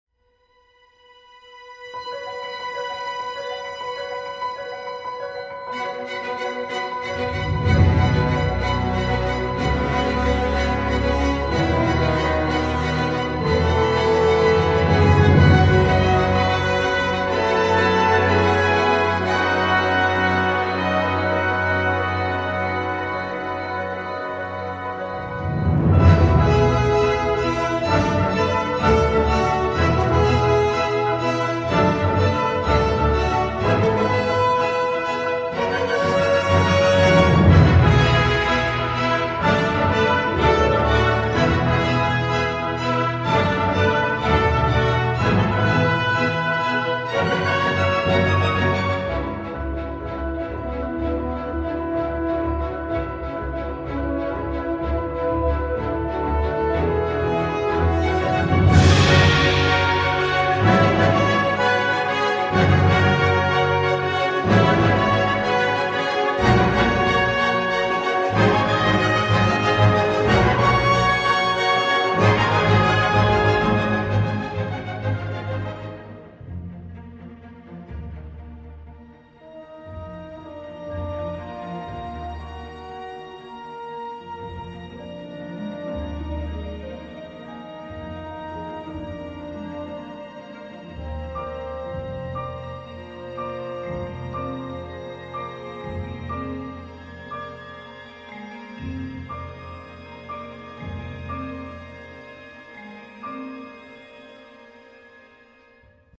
trompette